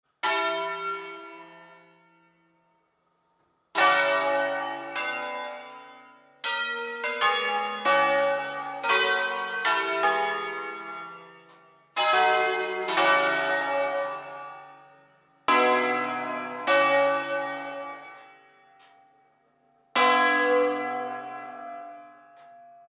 Entrando nello specifico di Bergamo, il suono ‘a scala’ si è sempre mosso sul principio del suono ‘al botto’, vale a dire producendo accordi invece di suoni alternati ed equidistanti:
La prima e la seconda suonano da sole e hanno funzione puramente decorativa.  La scala parte sostanzialmente con III e IV.